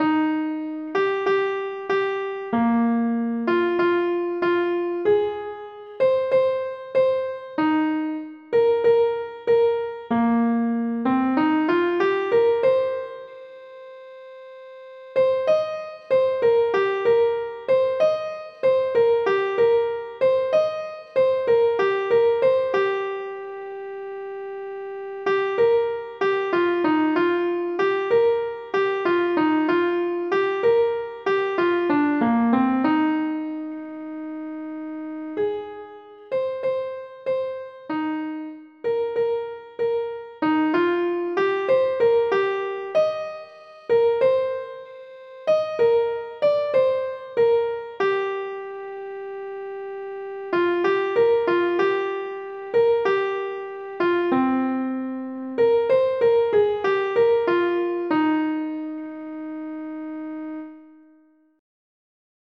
純音樂